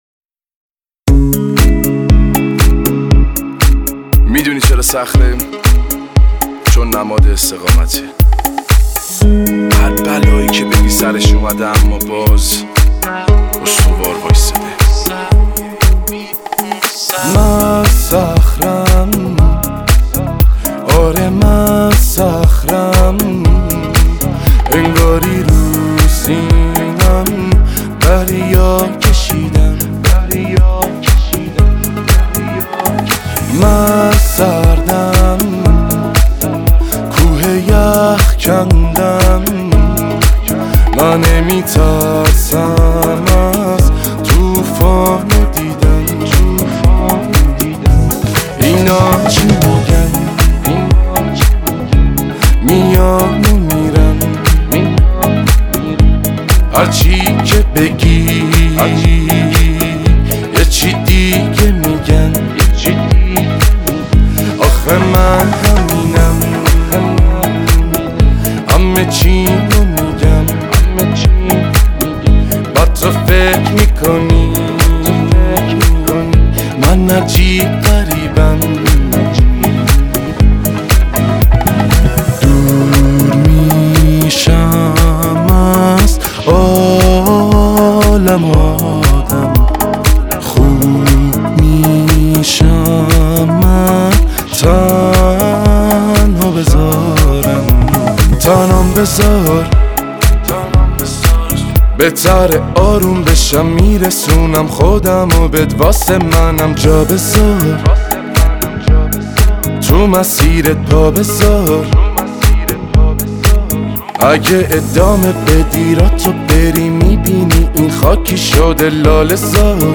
پاپ
آهنگ رپ